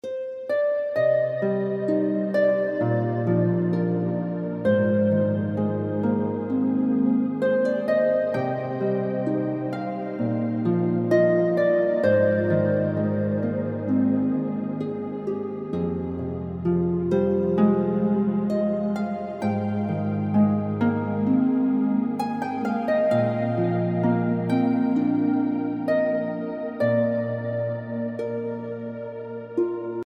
Heavenly Harp Music
harp